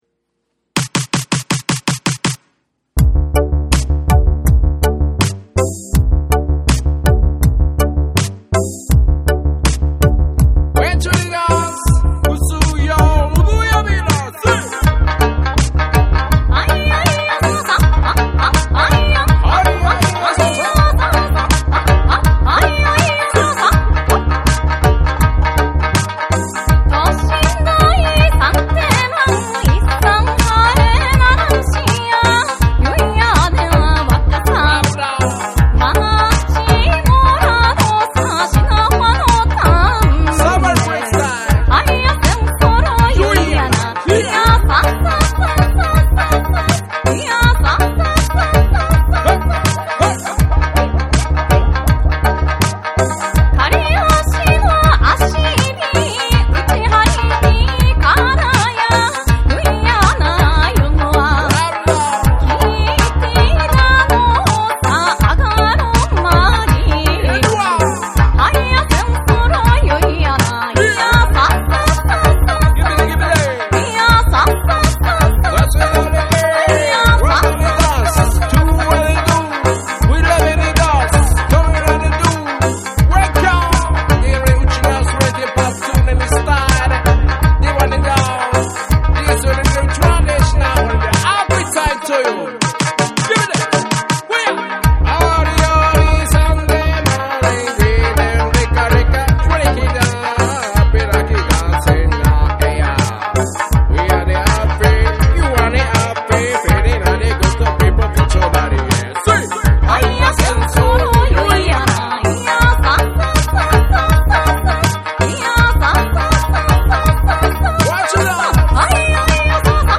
前作よりもさらにダンサブルな内容となっている。
琉球民謡に潜在するうちなーんちゅ独自のリズム感覚とジャマイカ産80年代ダンスホール・リディムの共鳴が証明された重要作。
JAPANESE / REGGAE & DUB